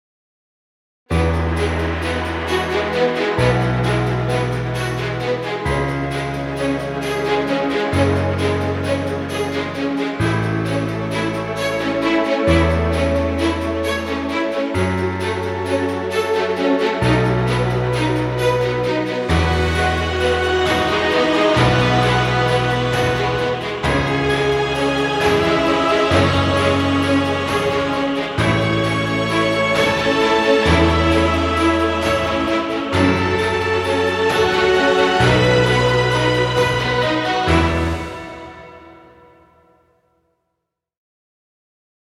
Cinematic track for piano, choir and orchestra.
Epic music, exciting intro, or battle scenes.
Cinematic dramatic music. Trailer music.